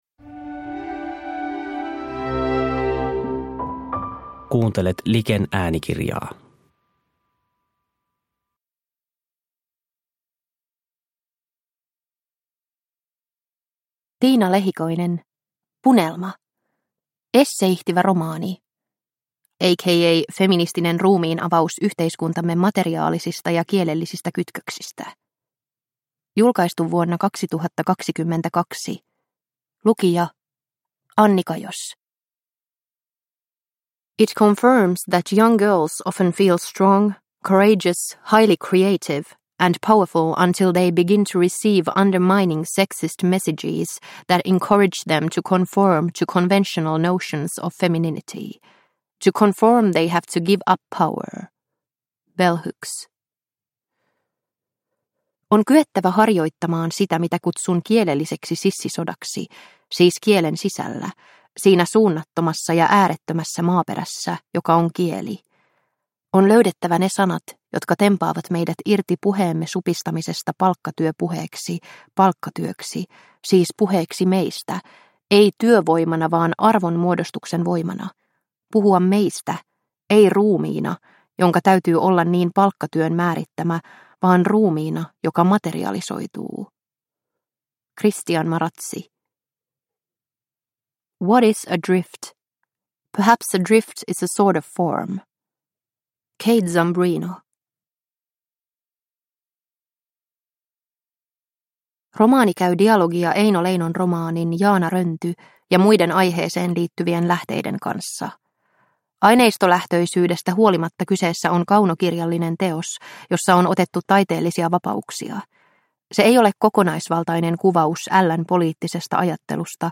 Punelma – Ljudbok – Laddas ner